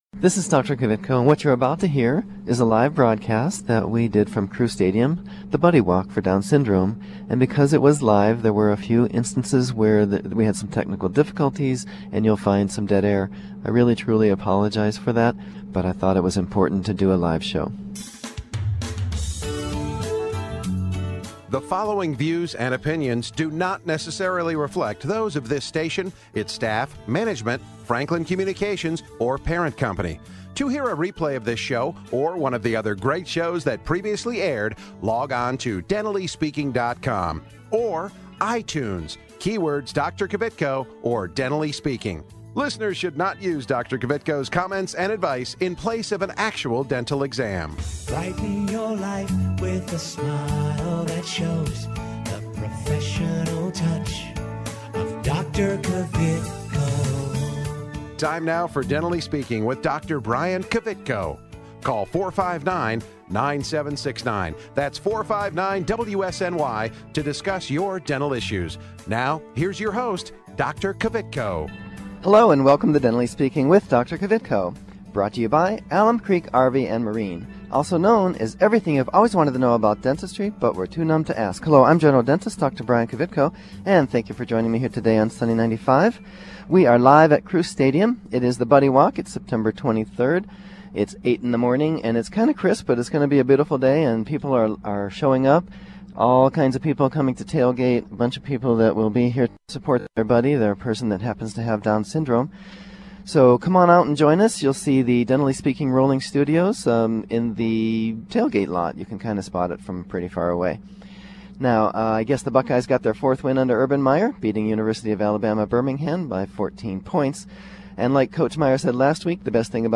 live remote from the Down Syndrome Association of Central Ohio Buddy Walk at Crew Stadium.